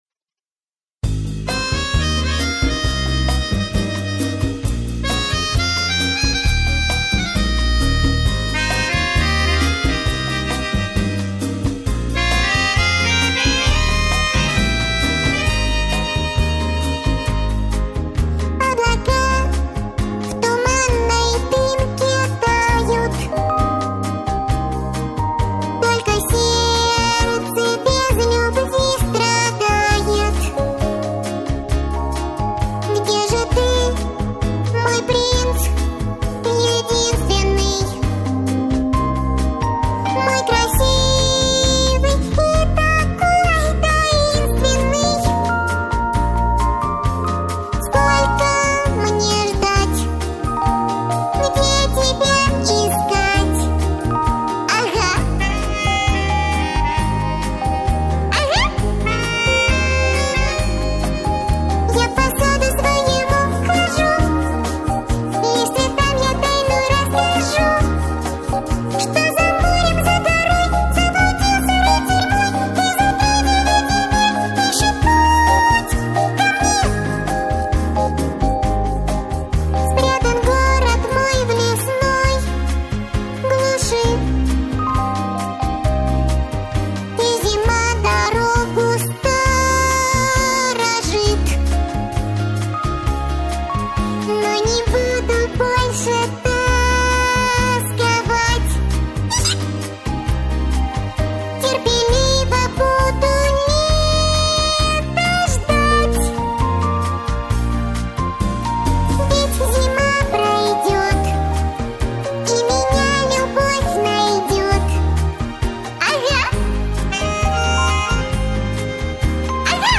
Music Director & Singer